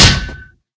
sounds / mob / zombie / metal1.ogg
metal1.ogg